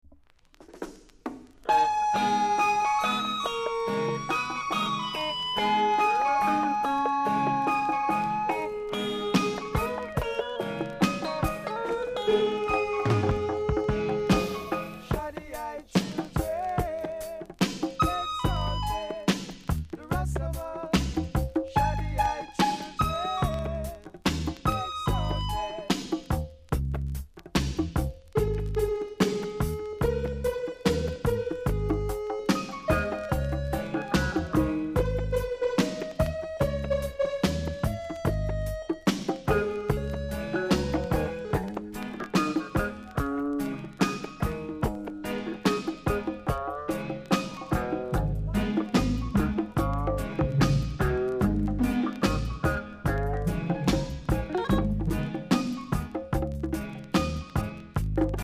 ※小さなチリ、パチノイズが少しあります。